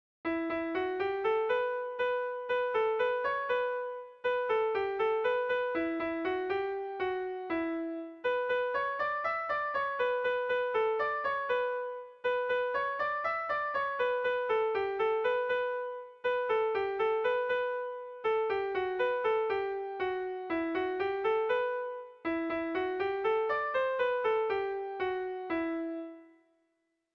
Air de bertsos - Voir fiche   Pour savoir plus sur cette section
Irrizkoa
ABD1D2B2EF